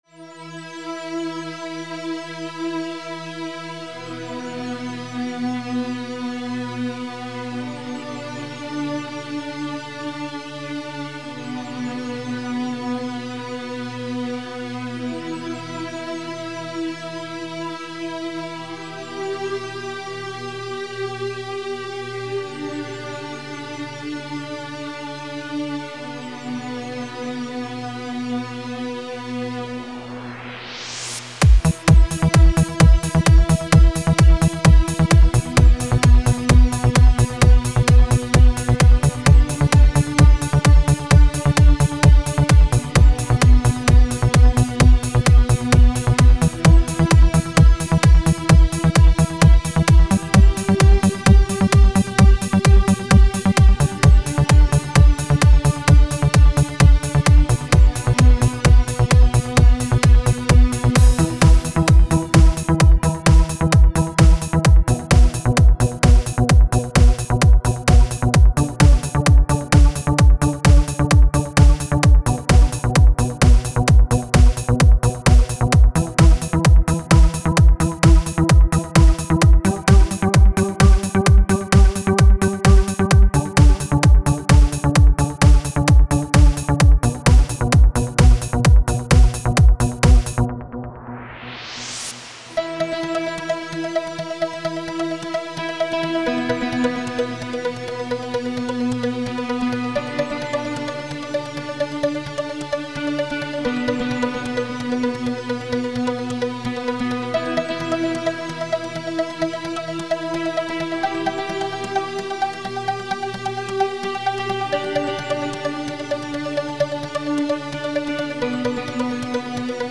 trance